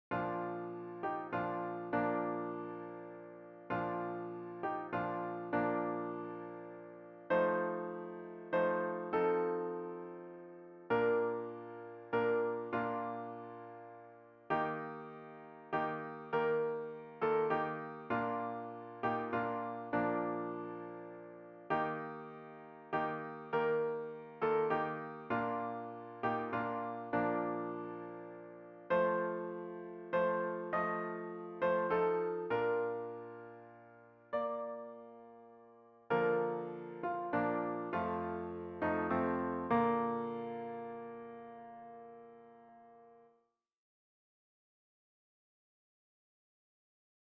Fichiers pour répéter :
Douce nuit soprano